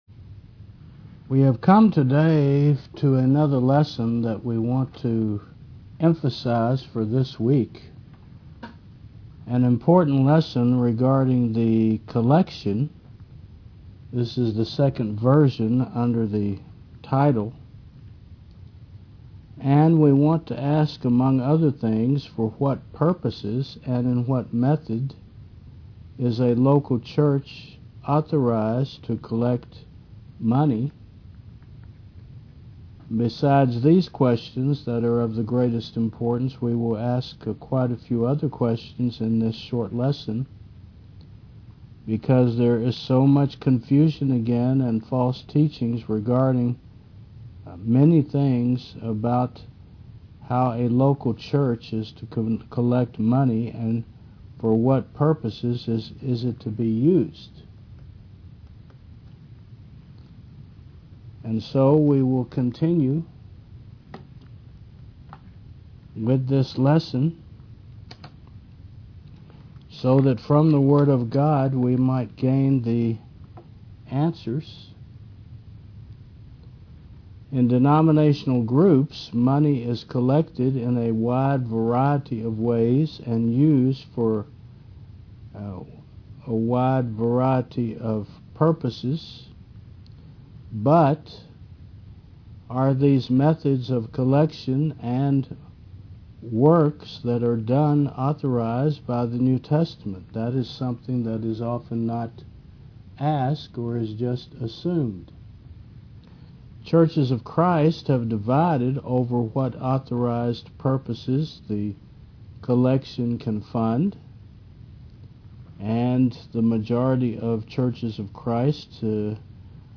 Faith Service Type: Mon. 9 AM When and how is a local church to collect funds and for what purposes are they to be used?